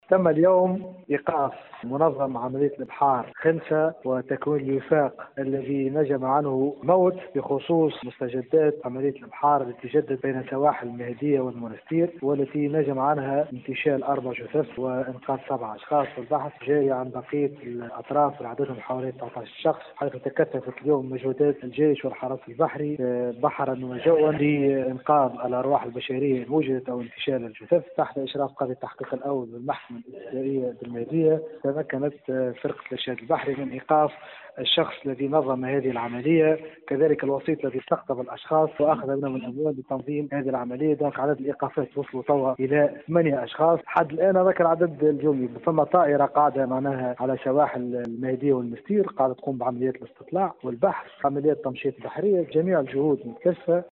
في تصريح ل “ام اف ام”، بأنه تم القبض على المنظم الرئيسي لعملية الهجرة غير النظامية على متن مركب صيد يقل 30 فردا غرق قبالة سواحل المهدية.